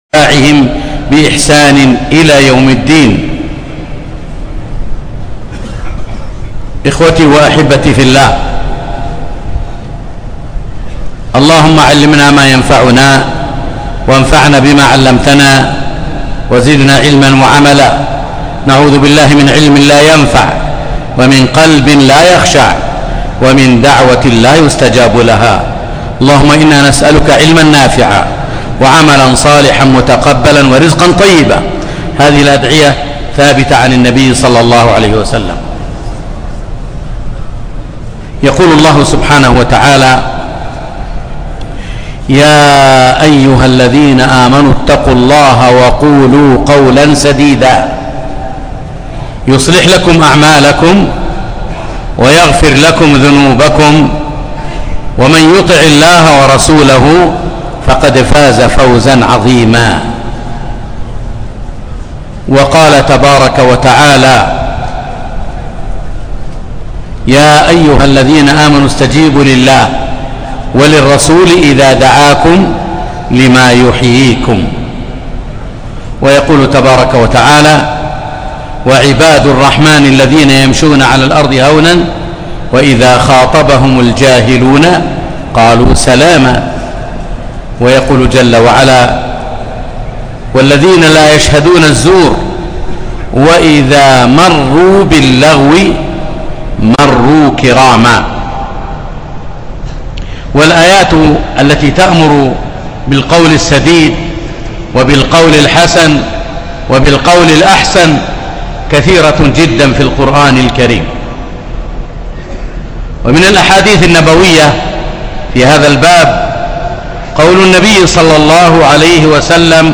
يوم الاحد 12 ذو الحجة 1438 الموافق 3 9 2017 في مسجد الخيف مشعر منى